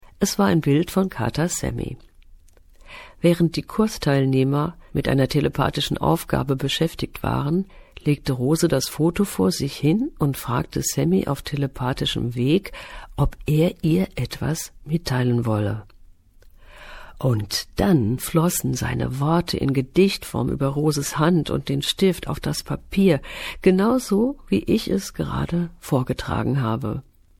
Deutsche Sprecherin Charakteristische, temperamentvolle, expressive und gefühlvolle Stimme Mittlere Stimmlage Stimme auf der CD Katzensehnsucht und CD E.T. 101 DAS KOSMISCHE HANDBUCH ZUR PLANETAREN (R)EVOLUTION
Sprechprobe: eLearning (Muttersprache):